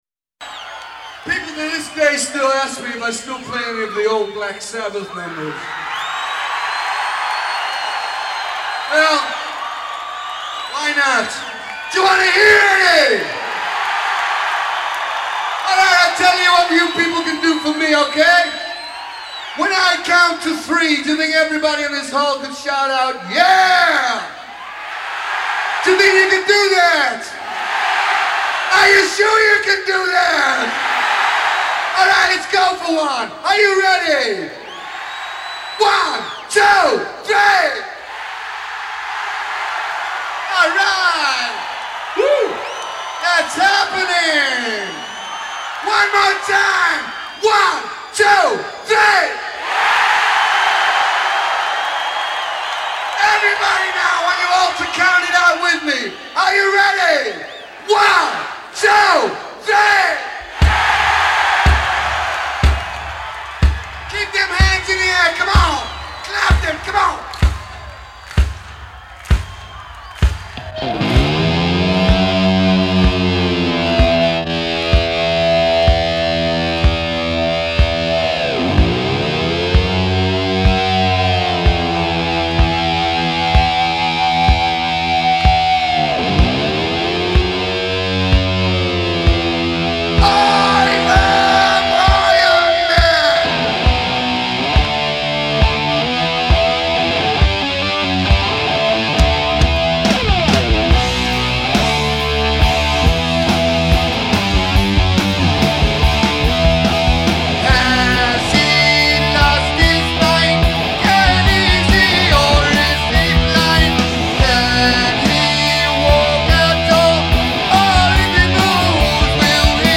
guitar
So this is absolutely live, no overdubs.